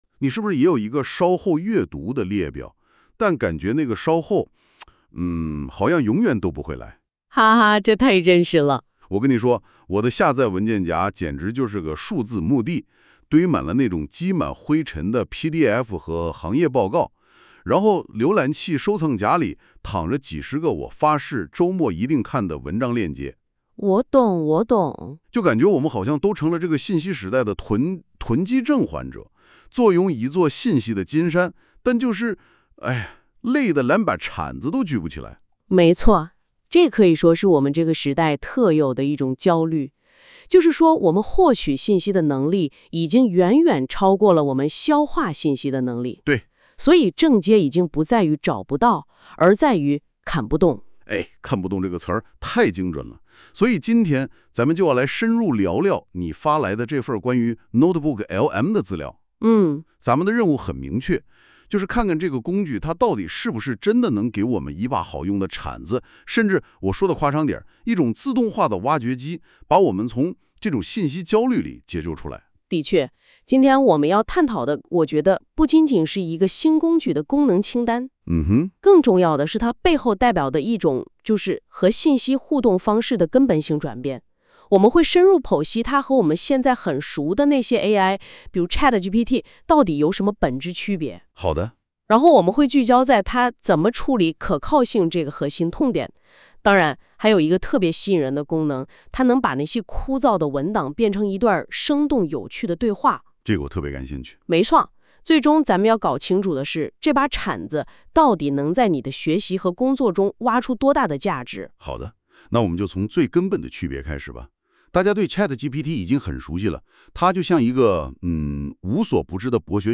效果展示 下面这段音频，是通过 NotebookLM 的音频概览功能将本文生成的播客式讲解，你可以直接点击播放快速感受效果： 点击收听音频：NotebookLM：让你的信息金山不再“啃不动” 什么是 NotebookLM？
奇迹发生了： 你会听到两个 AI 主持人（一男一女）开始对话。他们不是在朗读课文，而是在 聊天 ！